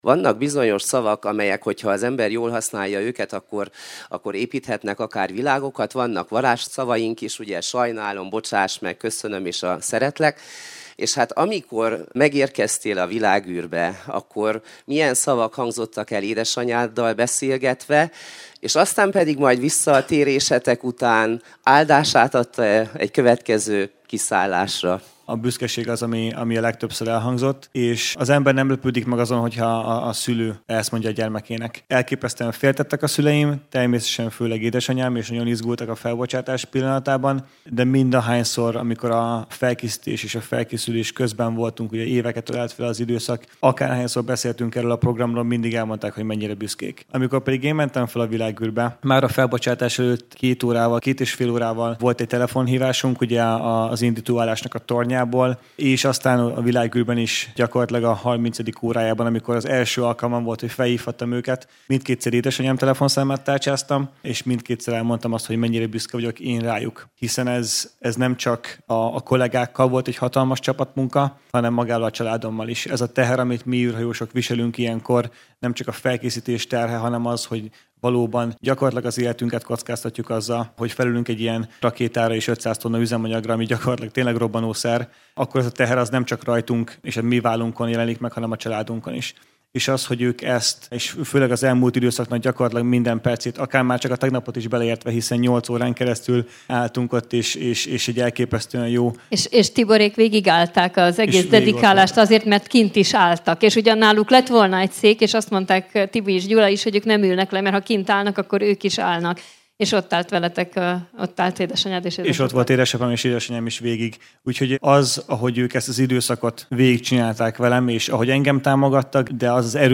A magyar kutatóűrhajóssal, aki Axiom–4-misszió tagjaként három hetet töltött június-júliusban a Nemzetközi Űrállomáson, a Kolozsvári Magyar Napok zárónapján találkoztunk magyar főkonzulátus udvarán, a Főtér 23-as programhelyszínen.